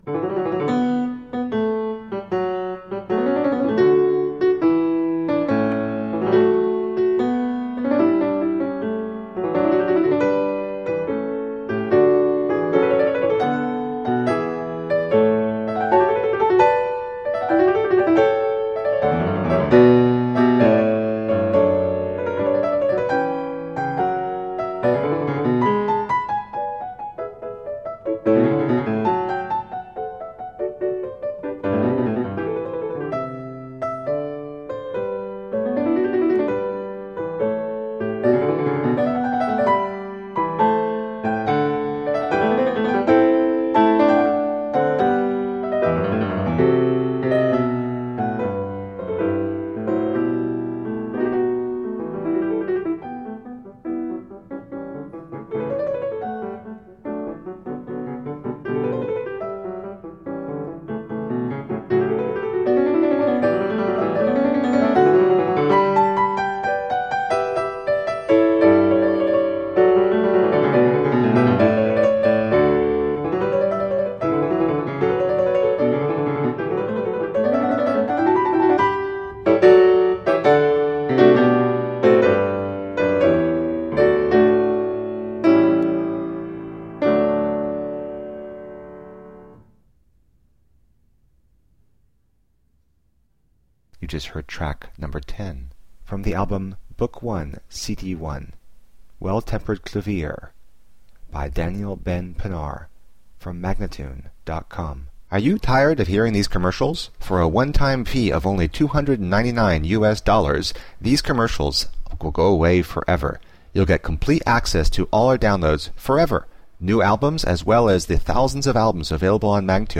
played with deep expressiveness and intelligence
solo piano music